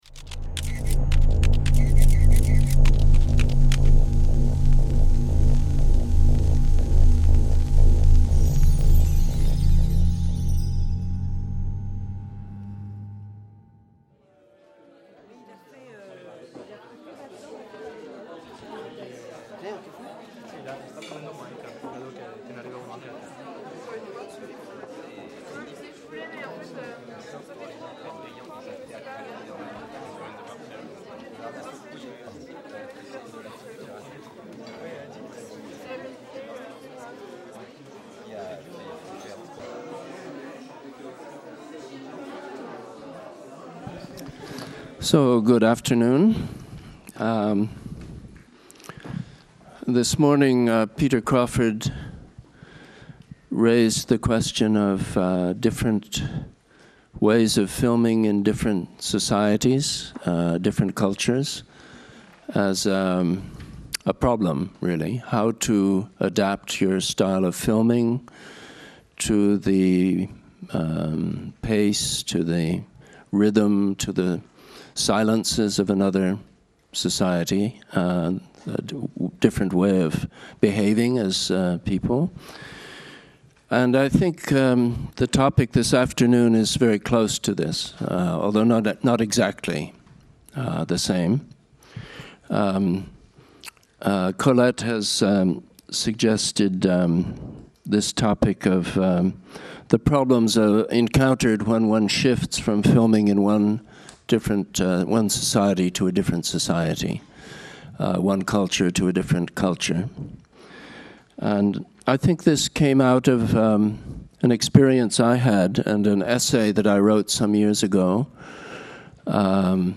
2 - Conférence (VF) avec extraits de films de David MacDougall | Canal U